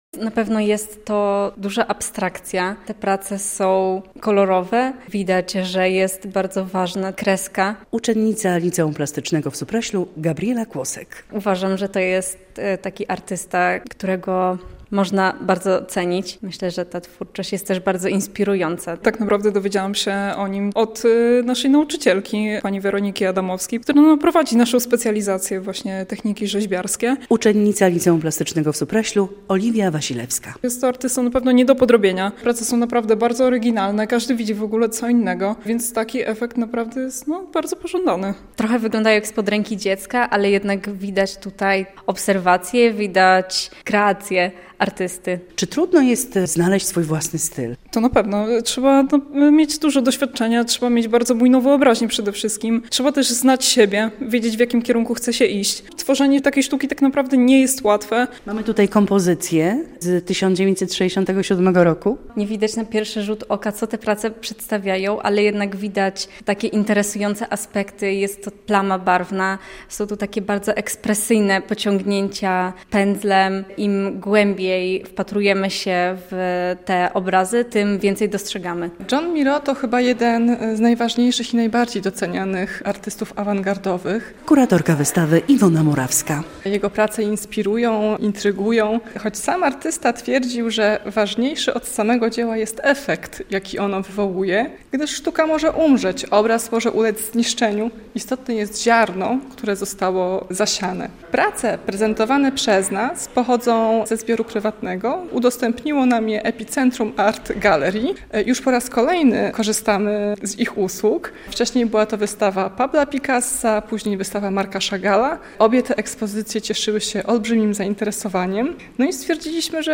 Wystawa w Ratuszu - relacja